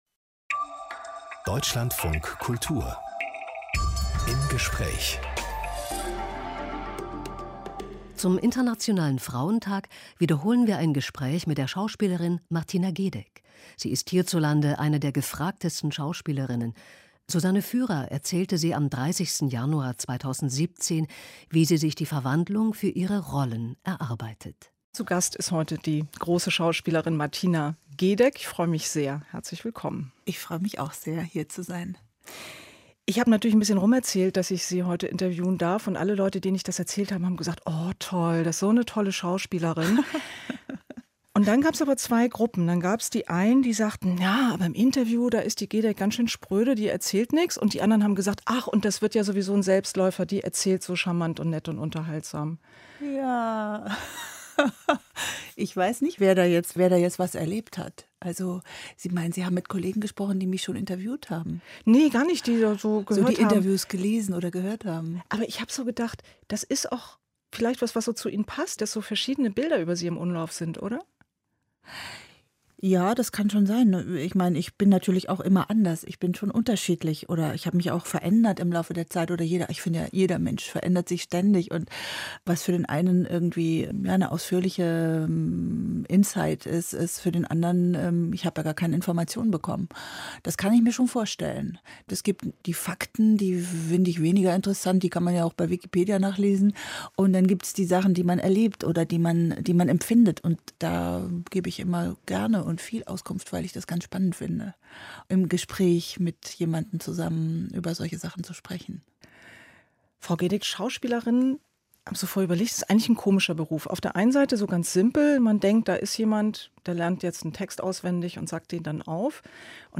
Zum Internationalen Frauentag wiederholen wir ein Gespräch mit der Schauspielerin Martina Gedeck. Sie ist hierzulande eine der gefragtesten Schauspielerinnen.